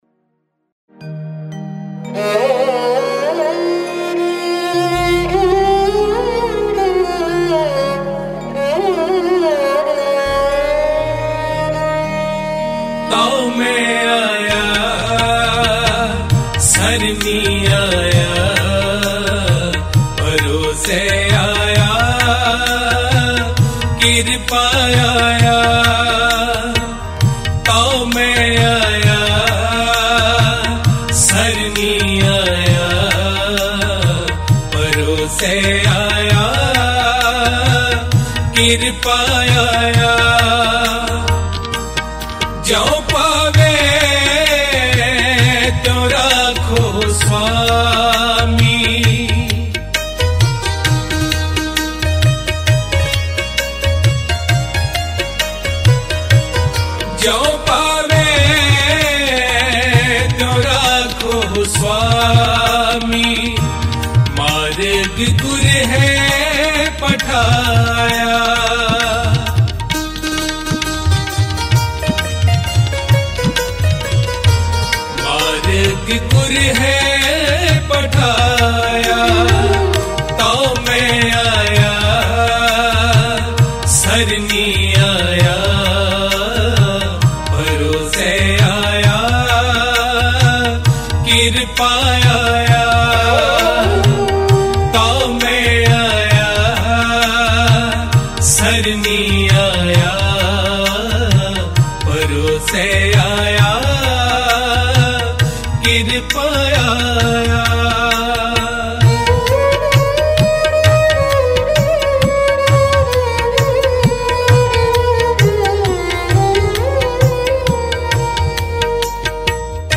Mp3 Files / Gurbani Kirtan / 2025 Shabad Kirtan /